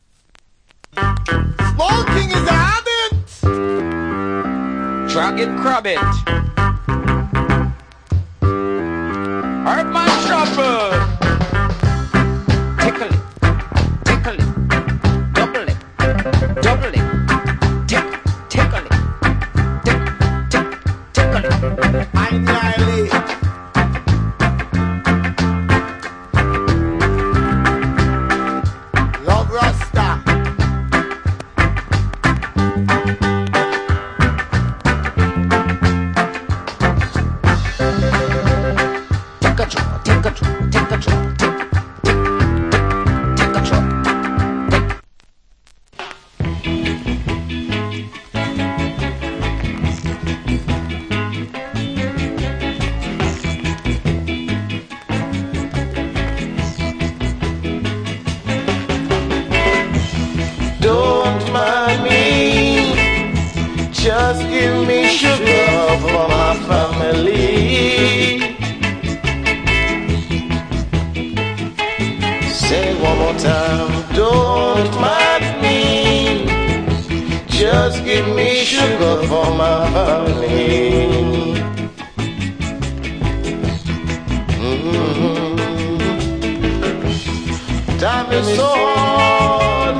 Killer MC + Organ Early Reggae Inst.